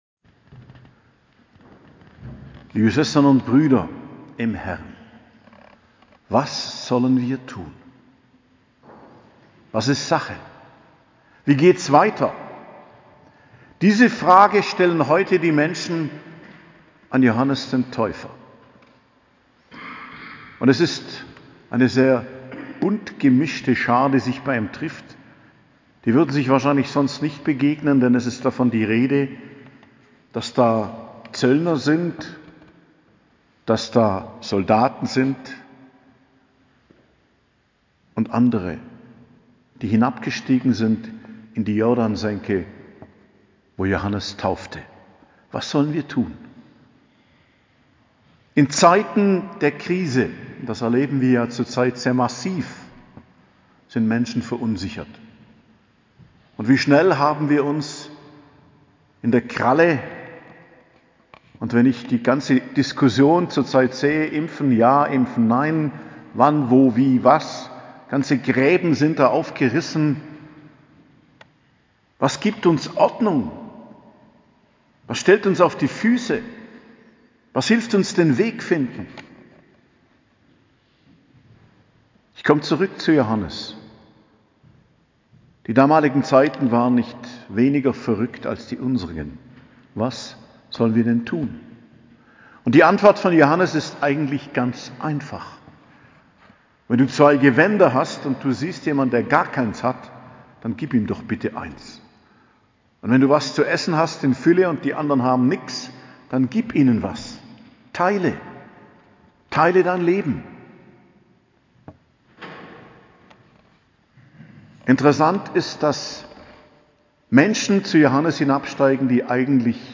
Predigt zum 3. Adventssonntag, 12.12.2021 ~ Geistliches Zentrum Kloster Heiligkreuztal Podcast